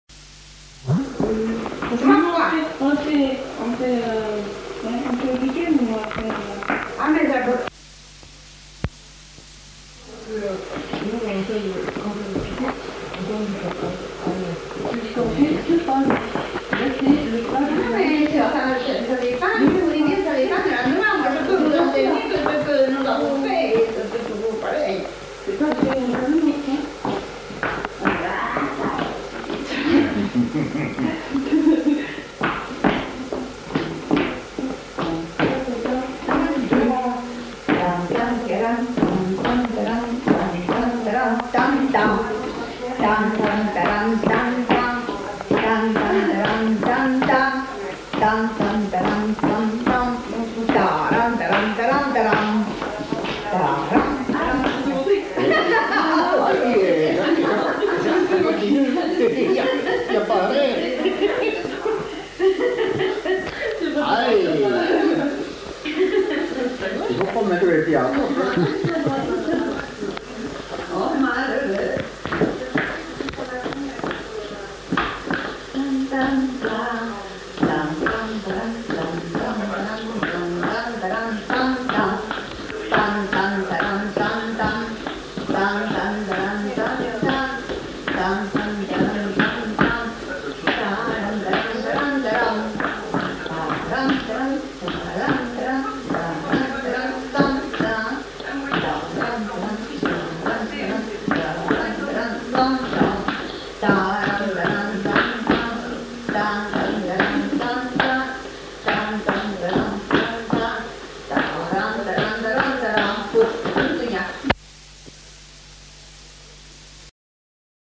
Aire culturelle : Haut-Agenais
Genre : chant
Effectif : 1
Type de voix : voix de femme
Production du son : fredonné
Danse : varsovienne
Notes consultables : Fredonne l'air tout en montrant les pas.